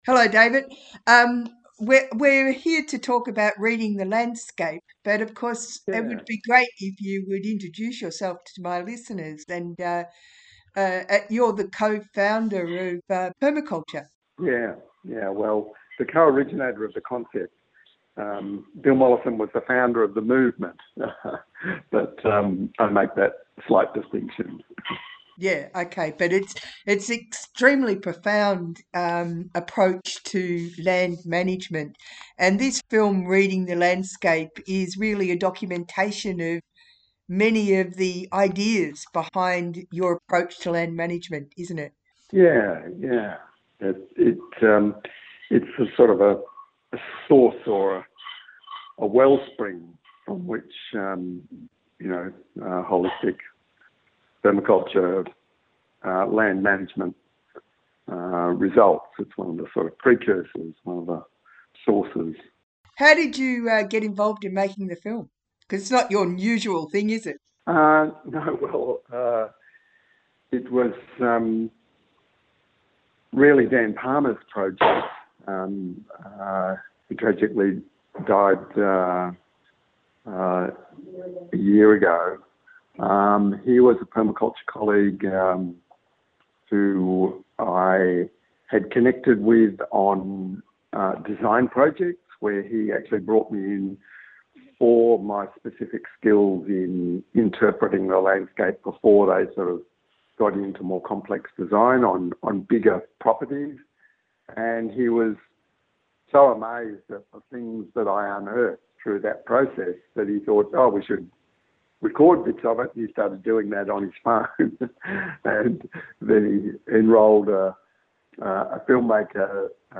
We chat with David Holmgren here, who is featured in the film Reading the Landscape.